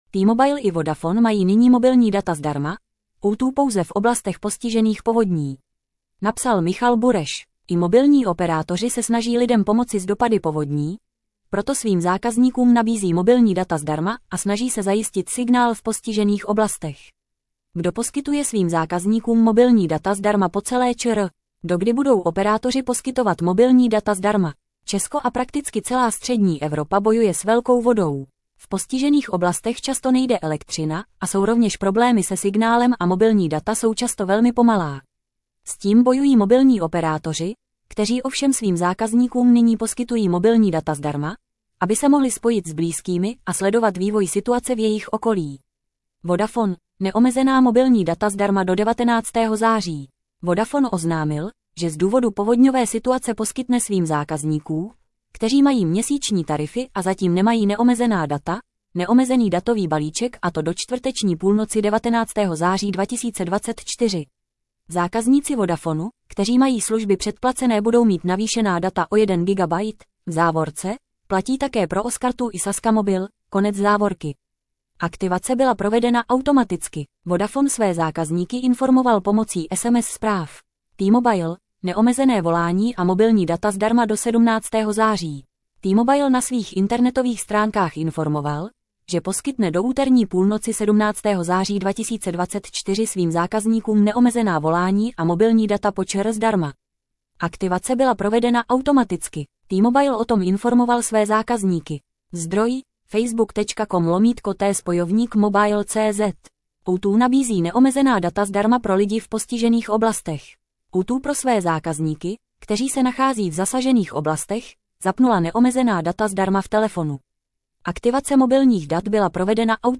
Tento článek pro vás načetl robotický hlas.